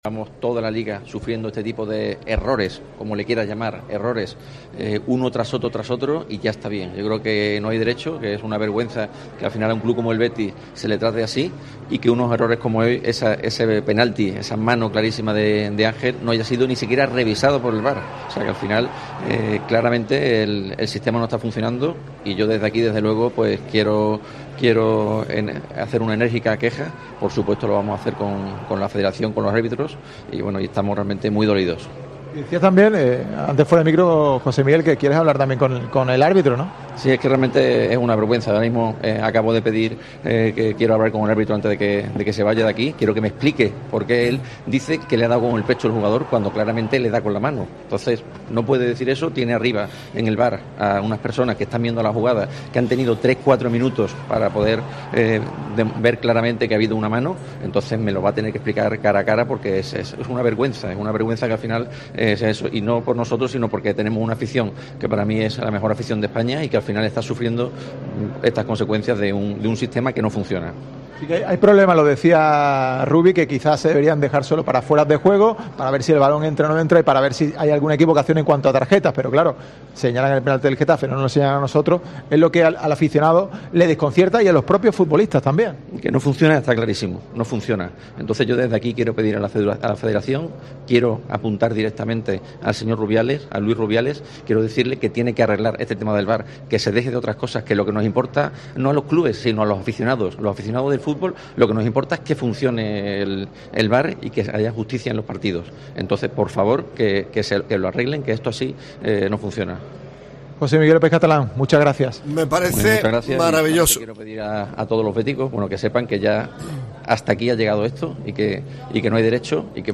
Los dirigentes verdiblancos hablan de errores arbitrales continuados y ponen el grito en el cielo